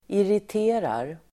Uttal: [irit'e:rar]